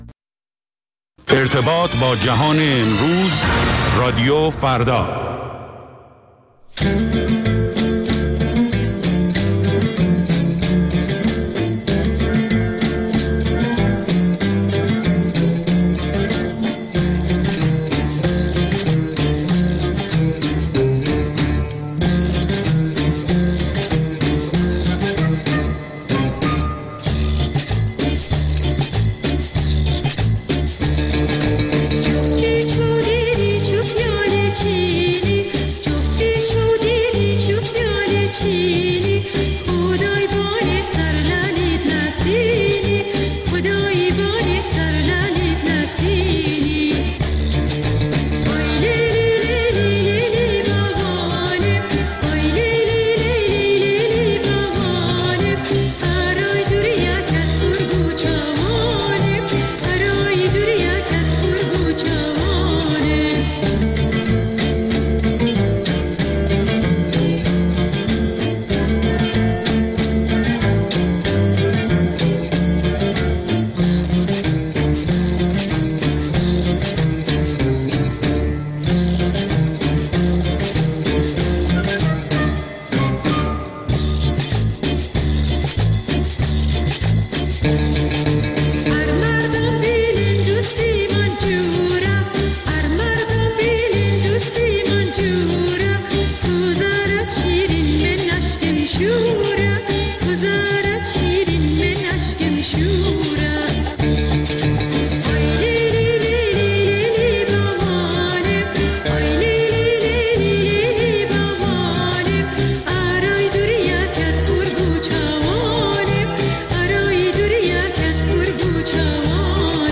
ویژه برنامه موسیقی محلی ایران